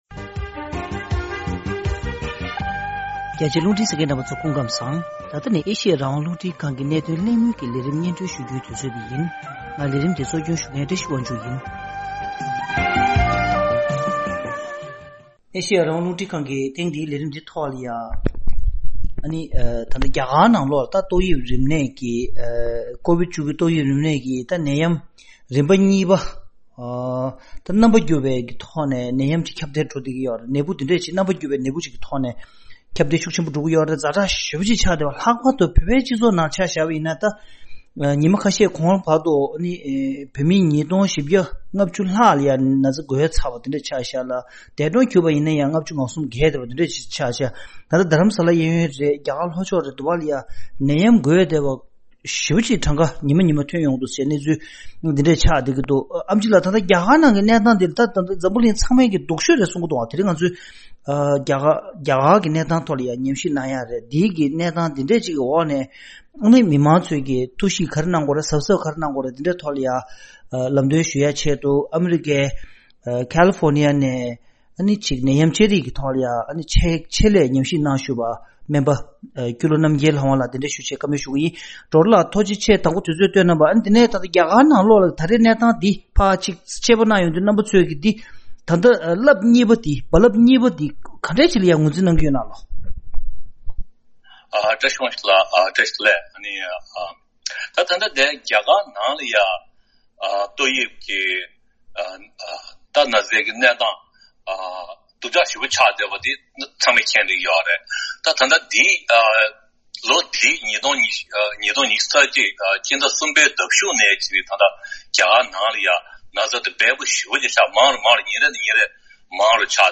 ཐེངས་འདིའི་གནད་དོན་གླེང་མོལ་གྱི་ལས་རིམ་ནང་། རྒྱ་གར་ནི་ད་ལྟའི་ཆར་ཀོ་ཝིཌ་ ༡༩ ཏོག་དབྱིབས་ནད་ཡམས་ཁྱབ་གདལ་གྱི་རྦ་རླབས་གཉིས་པ་འཕྱུར་སའི་ས་ཚིགས་ཛ་དྲག་ཆེ་ཤོས་ཤིག་ཏུ་གྱུར་ཡོད་པ་རེད།